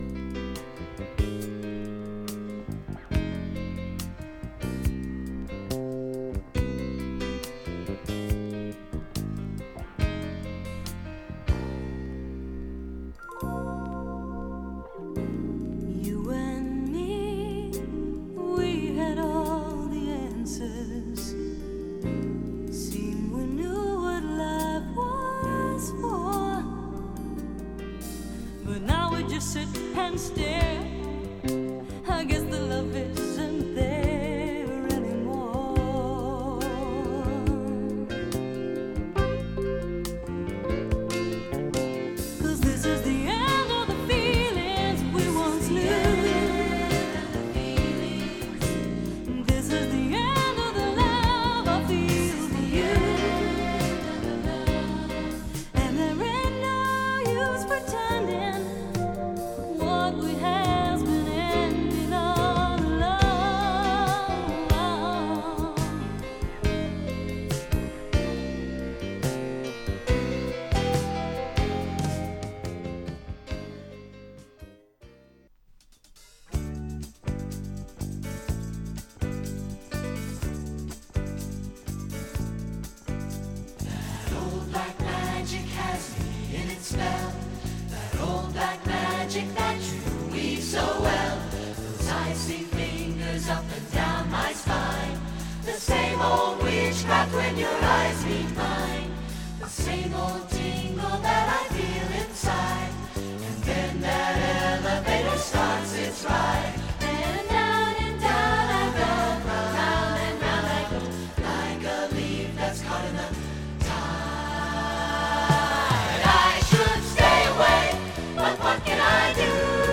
アンノウンな学生モノ・コンテンポラリー作品。